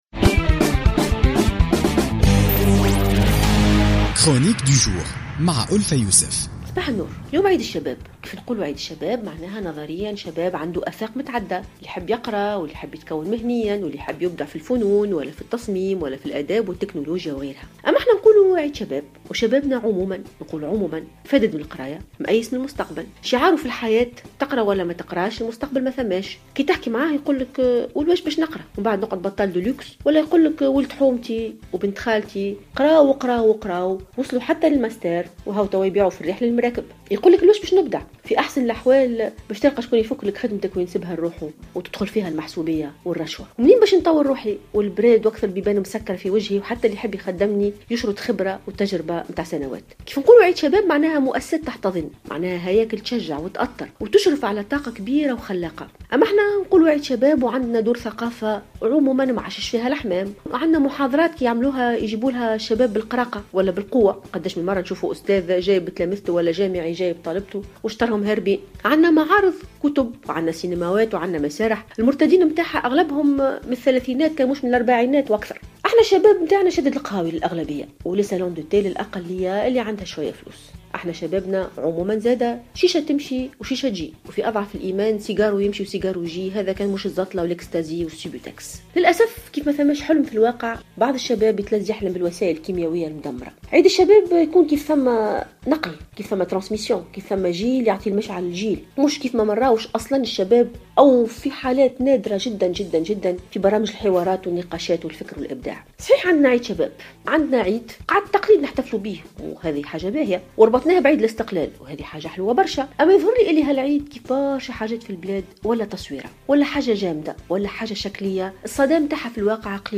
تطرقت الباحثة ألفة يوسف في افتتاحية اليوم الاثنين 21 مارس 2016 إلى الوضعية التي يعيشها الشباب في تونس.